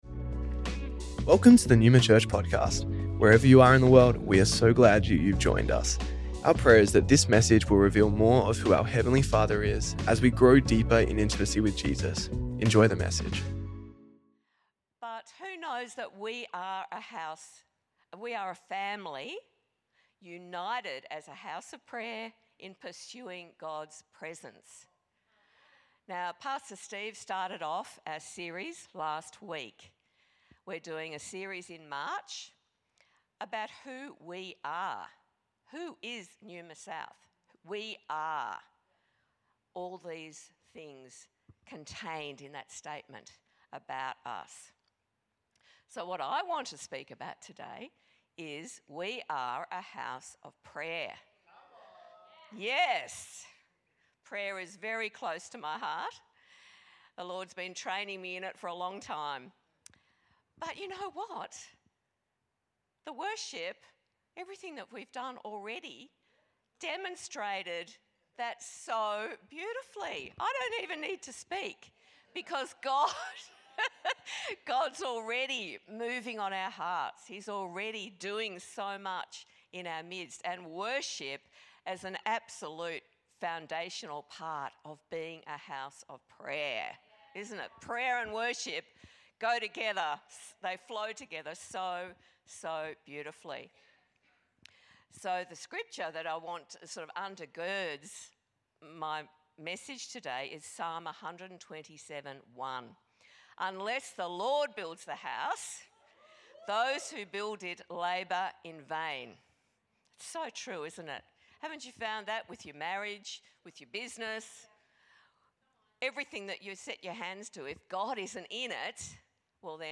Neuma Church Melbourne South Originally Recorded at the 10AM service on Sunday 9th March 2025